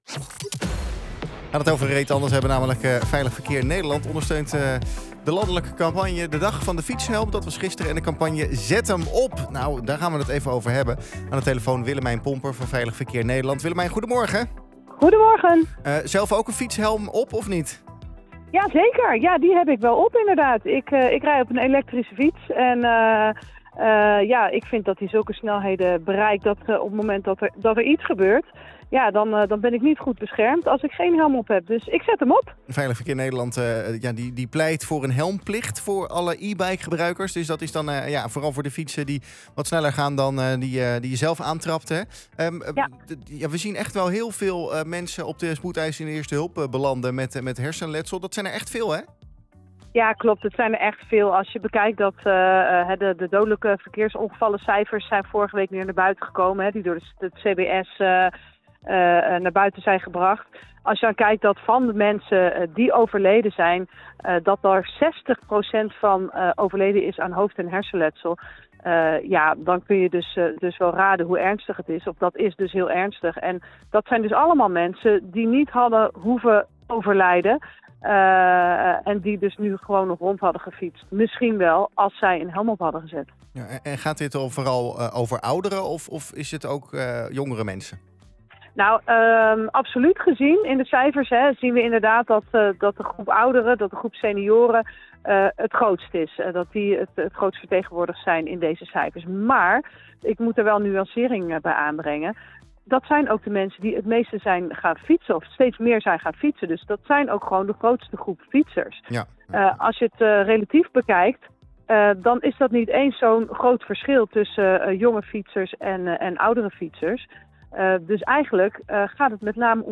in de Centraal + Ochtendshow over het belang van fietshelmen: